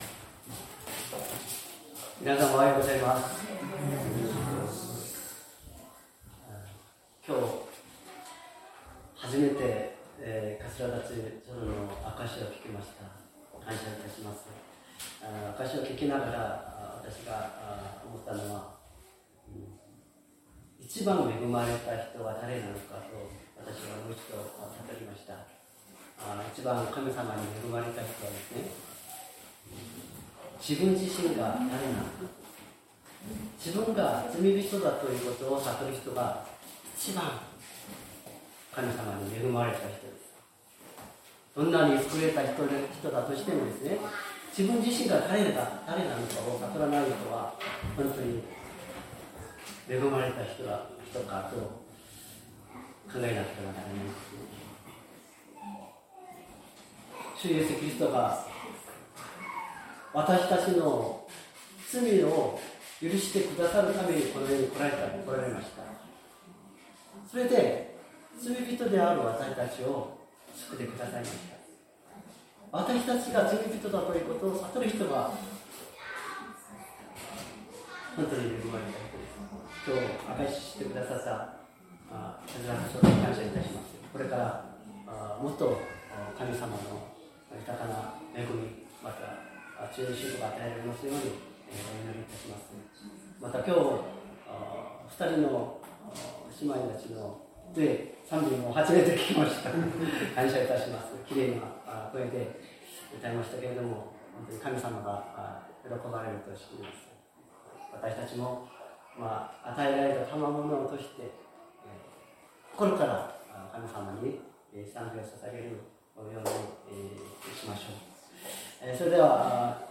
善通寺教会。説教アーカイブ 2025年05月25日朝の礼拝「休ませてあげよう」